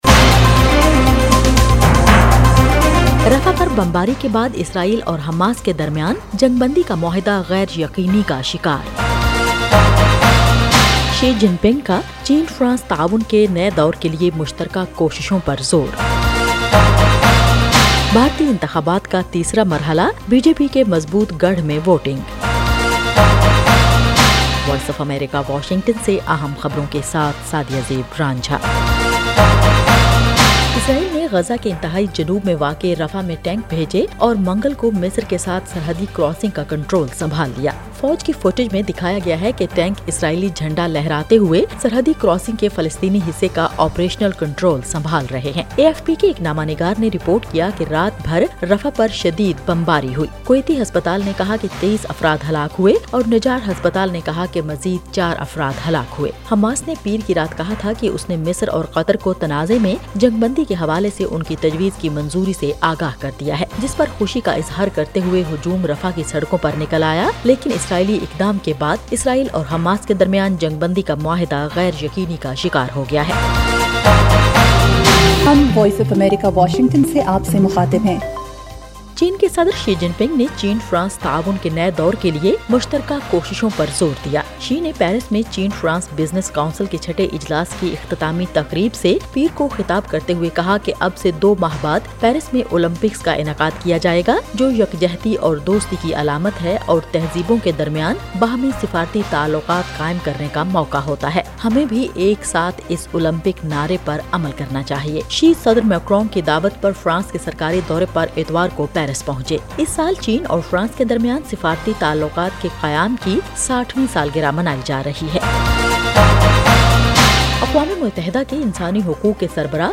ایف ایم ریڈیو نیوز بلیٹن :شام 6 بجے